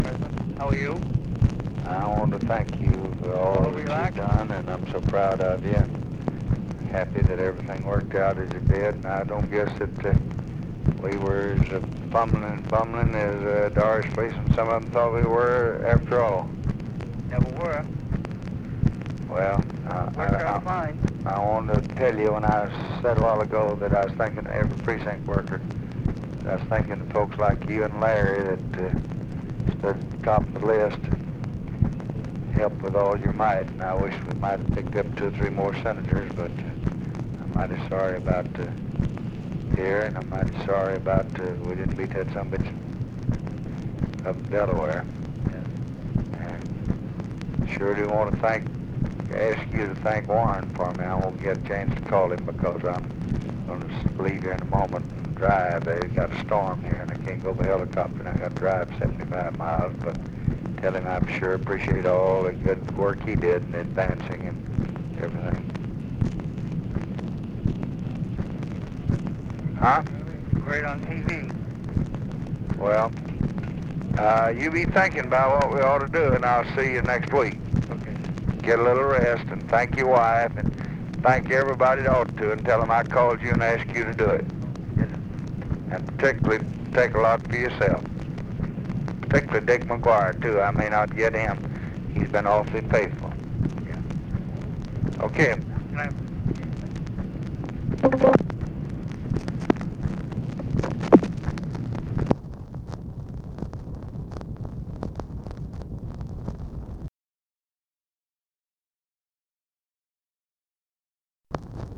Conversation with KEN O'DONNELL, November 4, 1964
Secret White House Tapes